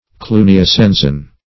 Cluniacensian \Clu`ni*a*cen"sian\, a.